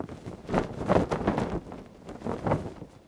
cloth_sail3.R.wav